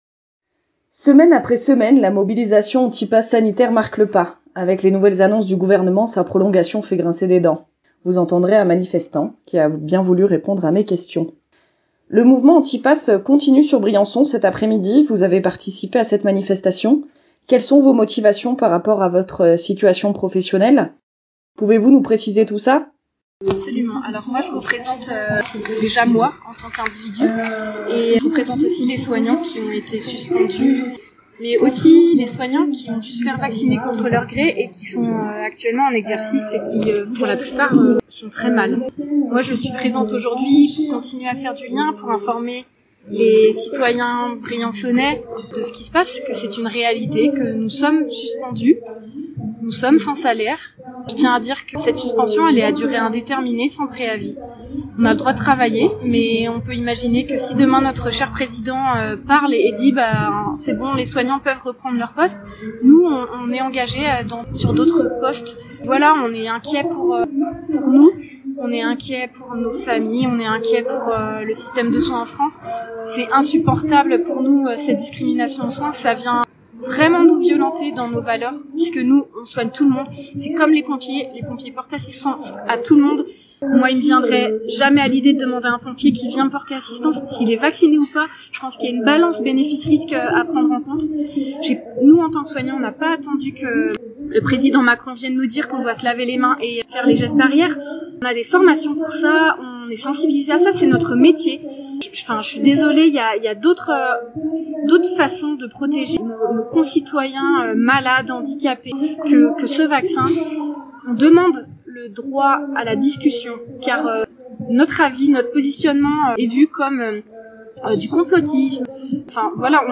Le pass sanitaire continu de mobiliser contre lui (1.6 Mo) Semaine après semaine, la mobilisation anti-pass sanitaire marque le pas. Avec les nouvelles annonces du gouvernement, sa prolongation fait grincer des dents. Vous entendrez un manifestant